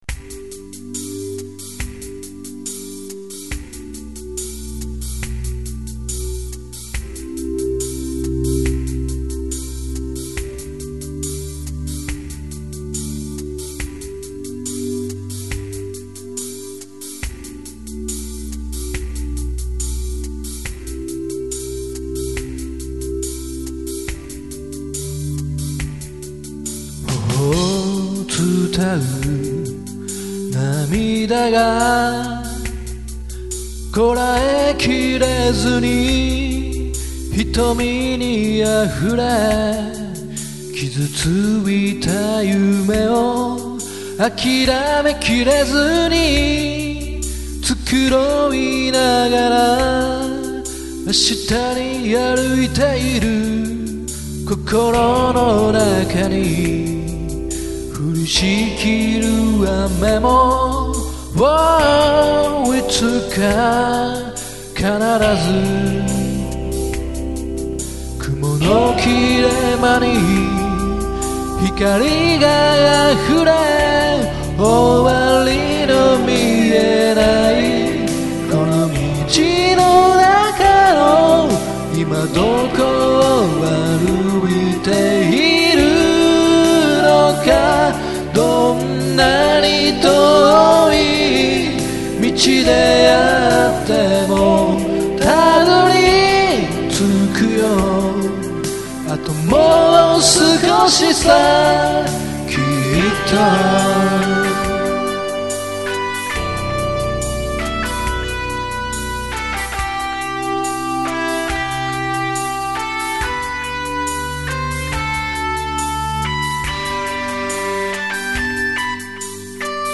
今回ギターの音色をリードもバッキングもあえて１種類シングルコイルフロントハーフの音で弾いた。
ピアノは手弾、ベースは指弾とスラップもどき、とドラムはV-Drumsで叩いた。パートも修正は最低限にした。